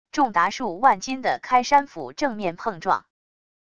重达数万斤的开山斧正面碰撞wav音频